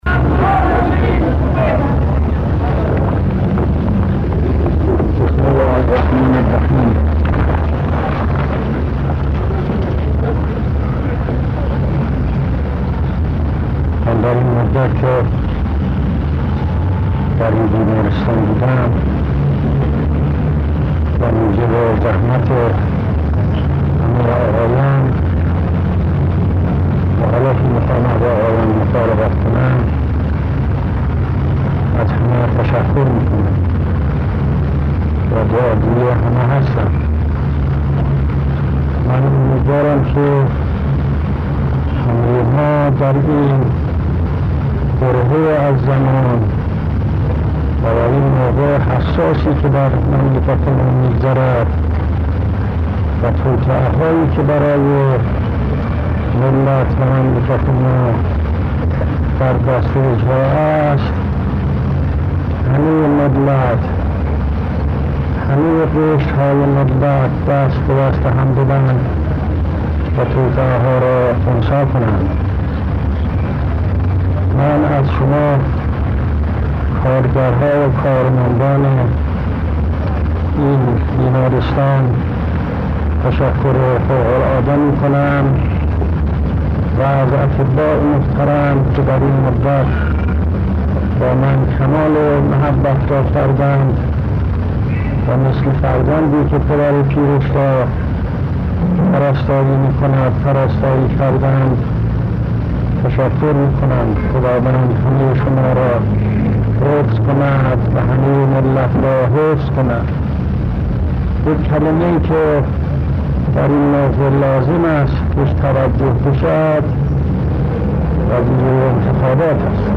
سخنرانی در جمع کارکنان بیمارستان قلب (انتخابات مجلس شورای اسلامی)